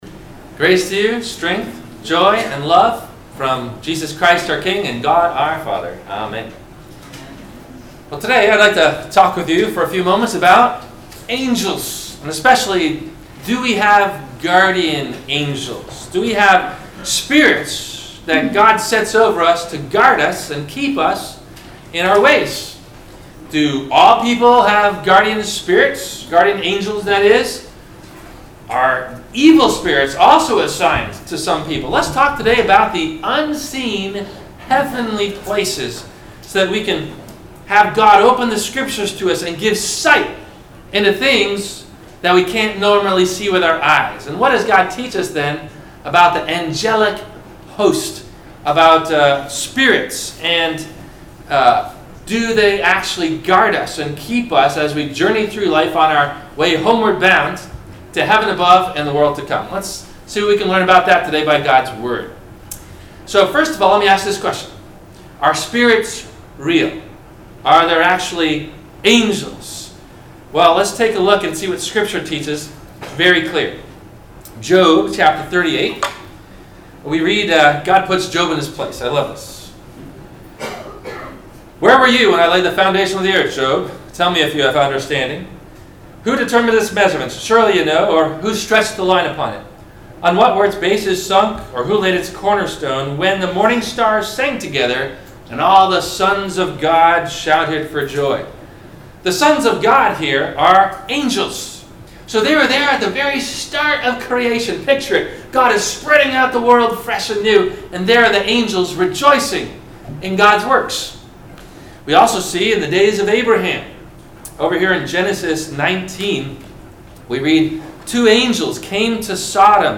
Do We Have Guardian Angels? – WMIE Radio Sermon – March 19 2018